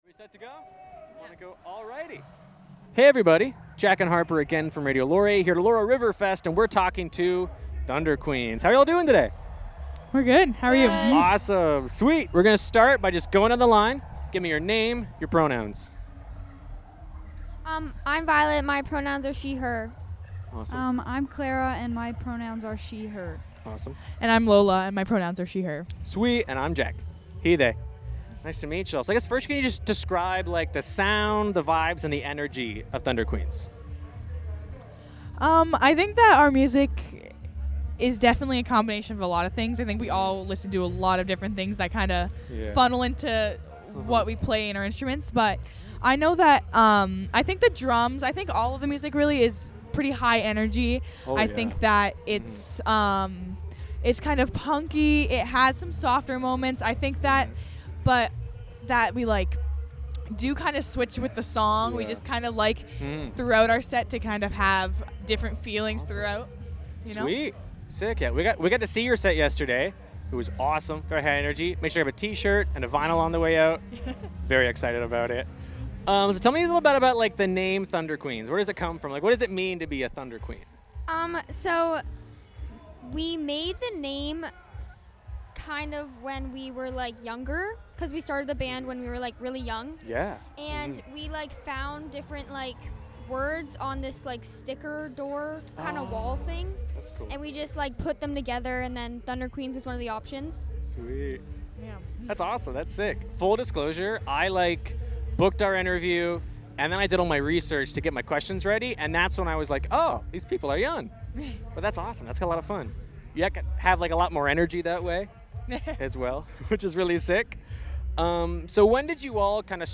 Band Interviews